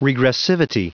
Prononciation du mot regressivity en anglais (fichier audio)
Prononciation du mot : regressivity